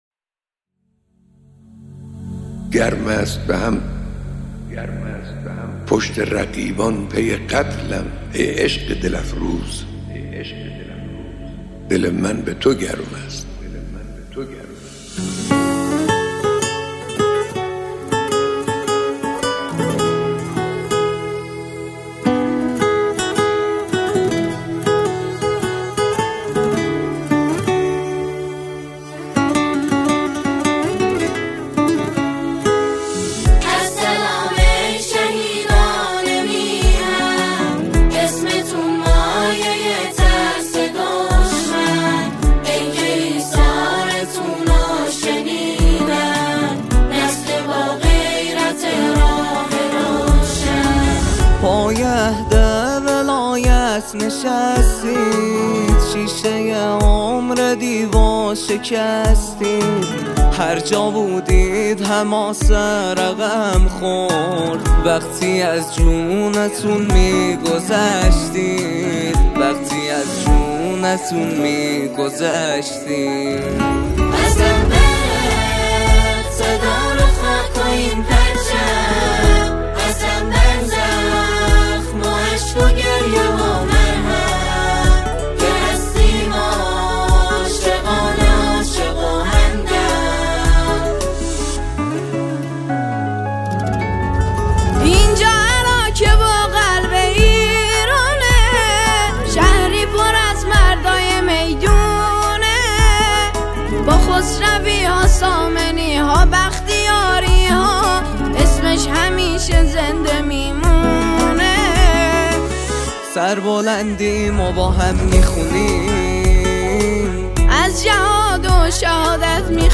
اثری میهنی، حماسی و عمیق است
ژانر: سرود